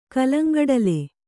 ♪ kallaŋgaḍale